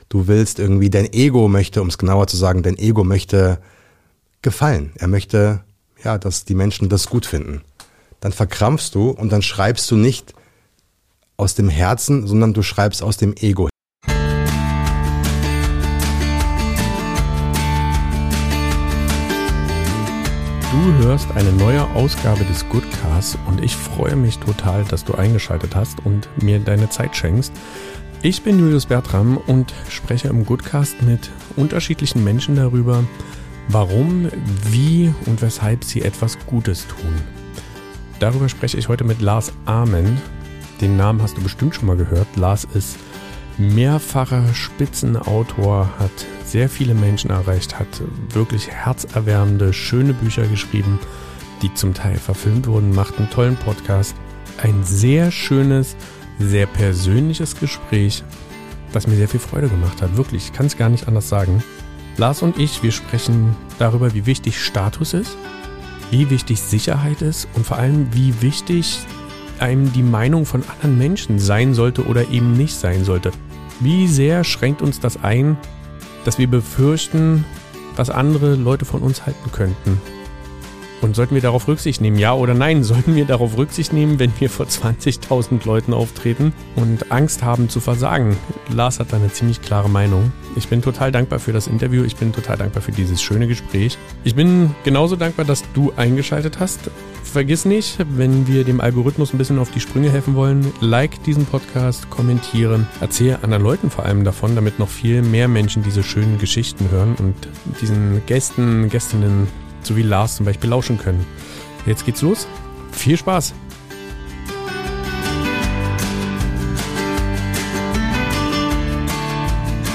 Ein sehr persönliches und überraschend ehrliches Gespräch mit einem Mann, der keine Angst davor hat, auch mal über Zweifel, Ängste und spirituelle Abzweigungen zu reden.